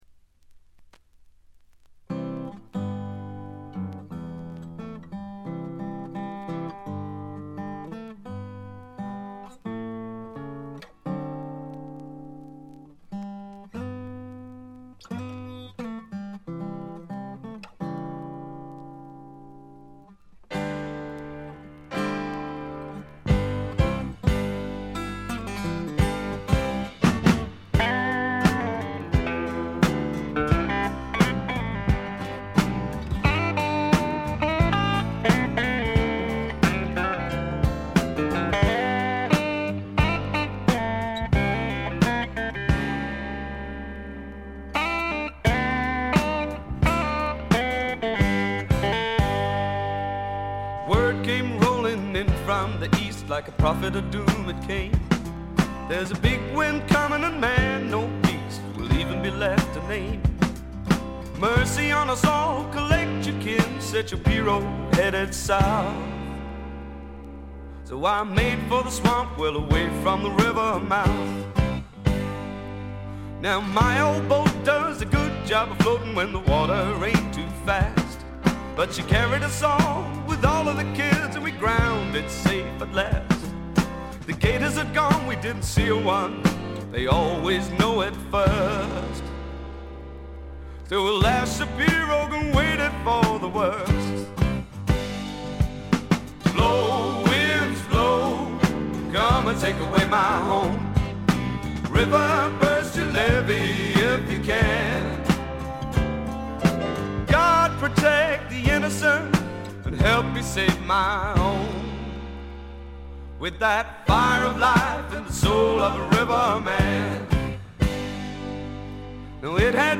静音部で軽微なチリプチ。散発的なプツ音少し。
思い切り泥臭さいサウンドなのにポップでキャッチーというのも素晴らしい。
試聴曲は現品からの取り込み音源です。